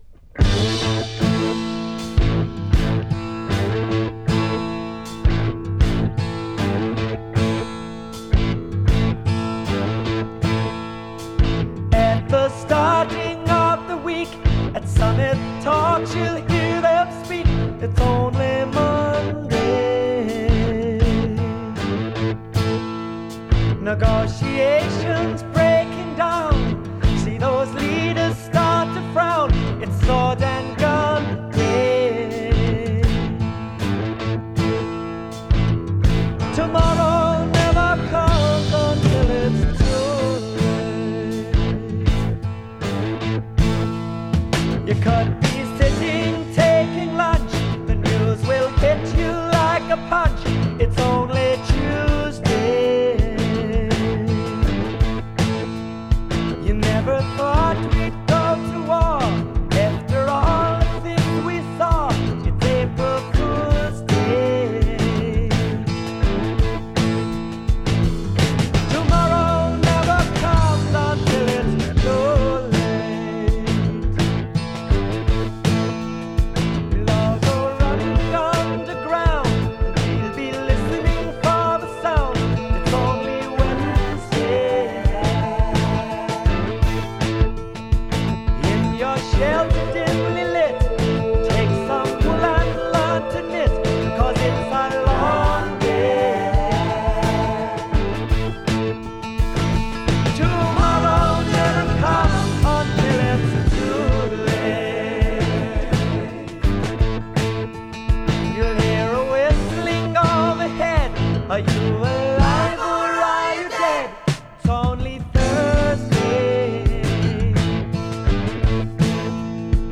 English rock band